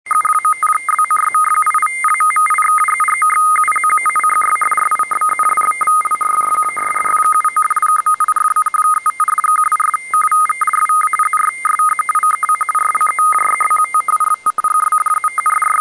Le fonctionnement est simple, il s'agit de transformer les caractères en deux fréquences audio distancé d'une espace précis dans le spectre des fréquences audio.
L'espacement entre les deux tonalités audio est variable mais sur un commun accord, les radioamateurs utilisent un espacement de 170Hz.
L'extrait audio représenté ici (fichier MP3) est un extrait à 75 bauds :
RTTY 75 bauds
rtty.mp3